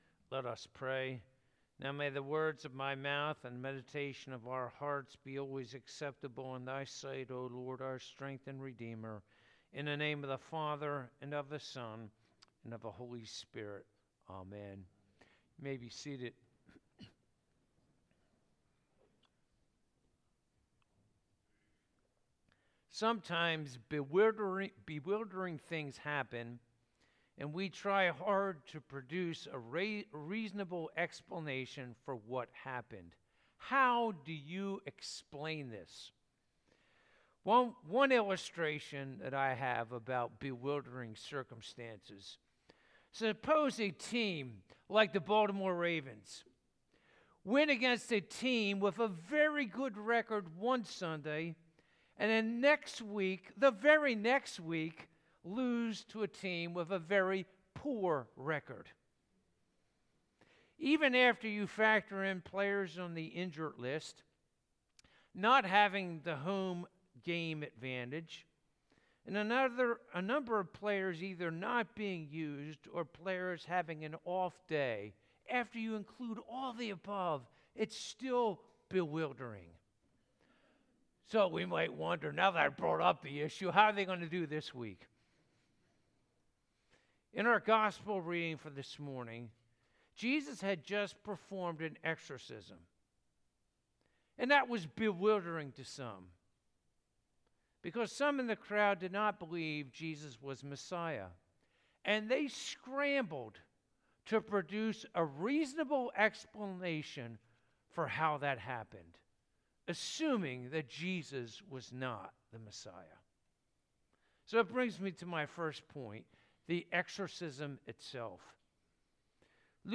Luke 11:14-28 Service Type: Sunday Morning « Lord